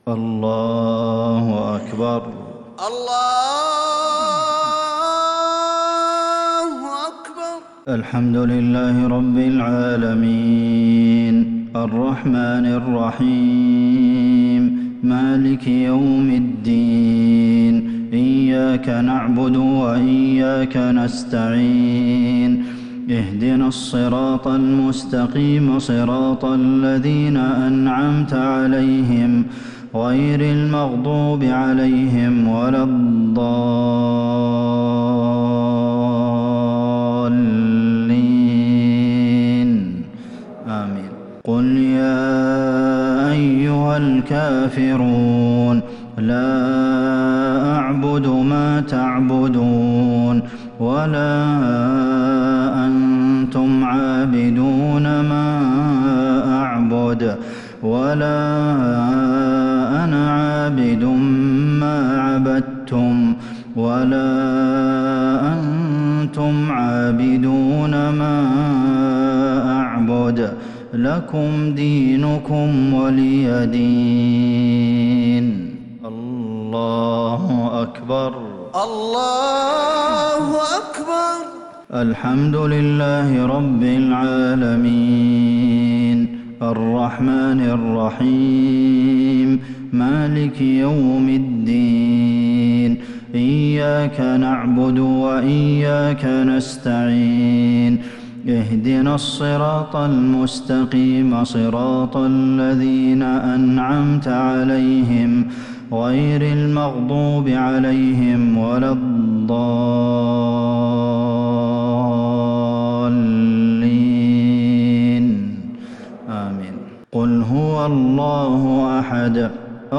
صلاة المغرب للشيخ عبدالمحسن القاسم 4 ربيع الأول 1442 هـ
تِلَاوَات الْحَرَمَيْن .